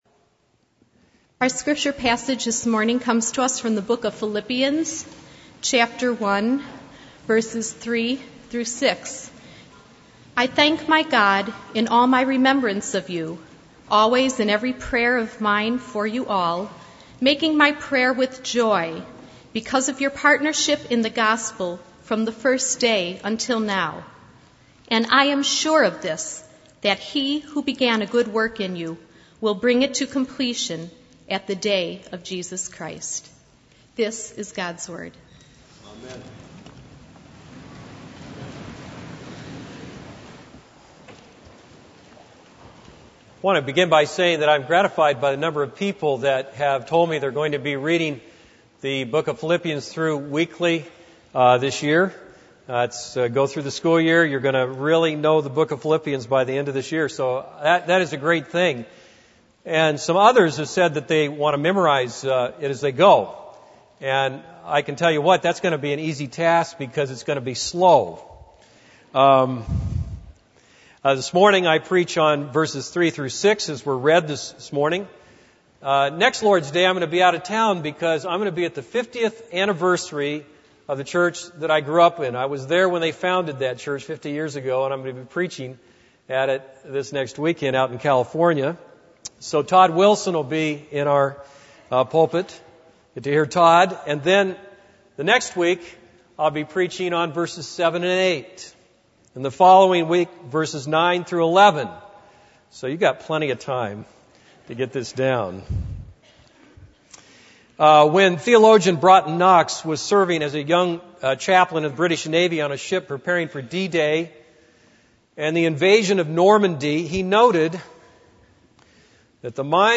This is a sermon on Philippians 1:3-6.